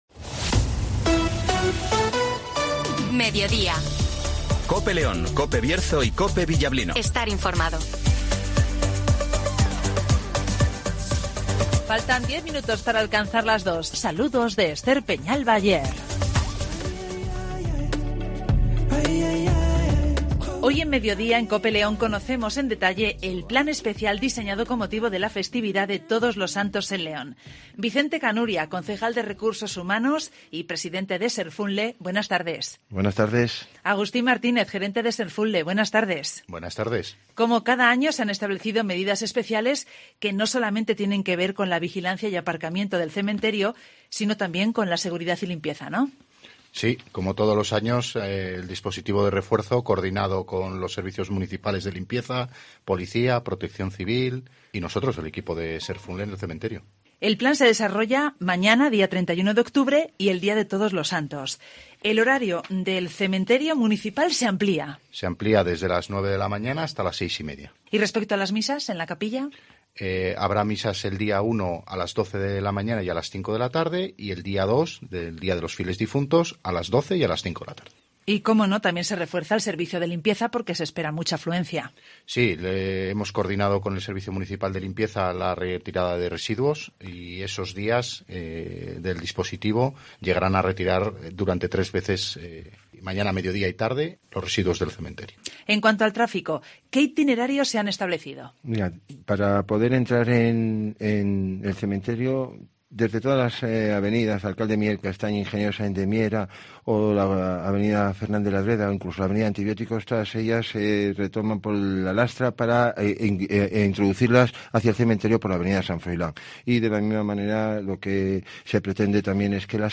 Vicente Canuria ( Concejal de " Recursos Humanos y Presidente de " Serfunle " )